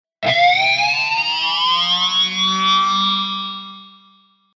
ロックな効果音をご自由にダウンロードして下さい。
Distortion Sound Guitar
Distortionギョォーン↑01 89.21 KB